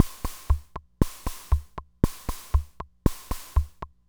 Index of /90_sSampleCDs/300 Drum Machines/FG Enterprises King Beat/FG Enterprises King Beat Sample Pack_Audio Files
FG Enterprises King Beat Sample Pack_Loop2.wav